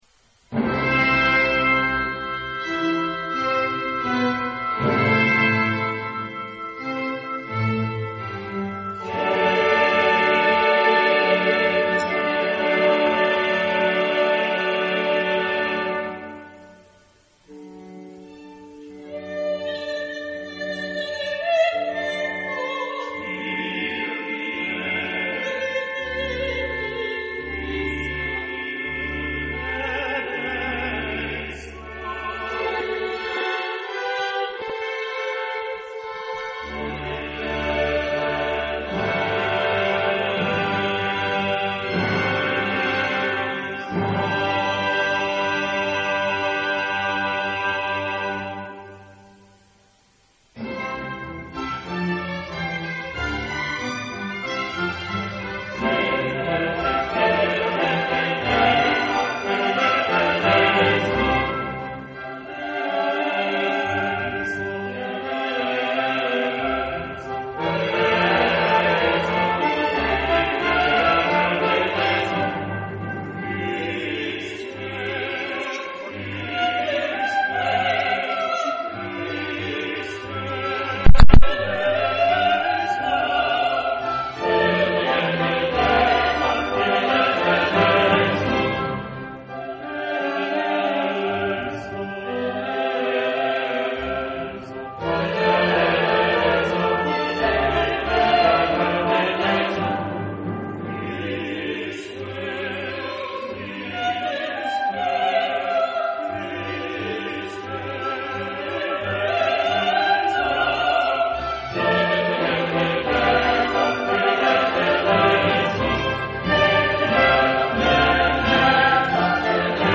Epoque: 18th century
Genre-Style-Form: Sacred ; Mass
Type of Choir: SATB  (4 mixed voices )
Soloist(s): satb
Instrumentation: Orchestra  (14 instrumental part(s))
Instruments: Oboe (2) ; Trumpet in C (2) ; Trombone (3) ; Timpani ; Violin I ; Violin II ; Cello (1) ; Double bass (1) ; Organ (1)